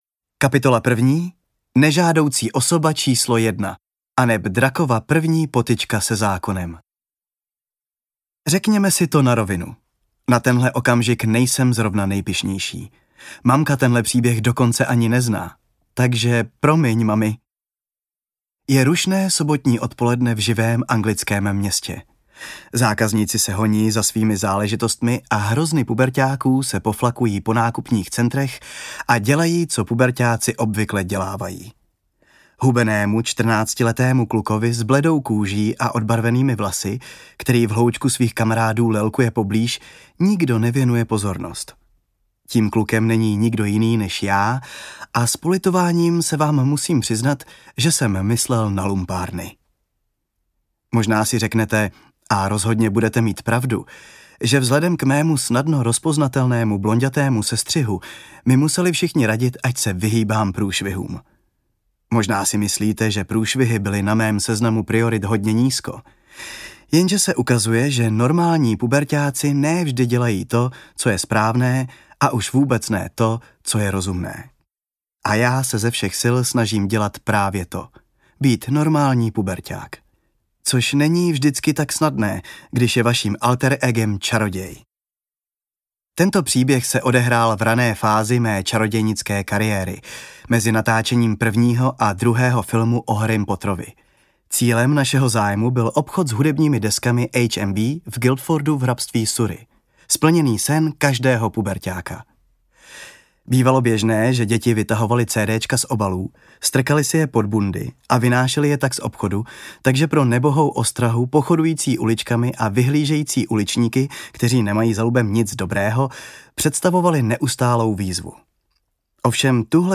audiokniha
Předmluva filmové Hermiony Emmy Watson i samotný text Toma Feltona je autenticky namluven jejich českými dabéry.